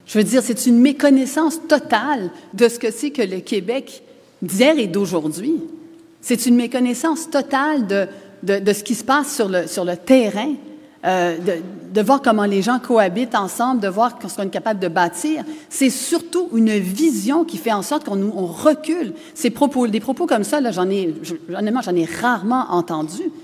C’est le point central du discours qu’elle a livré lors de son passage dans la circonscription de La Pinière ce jeudi.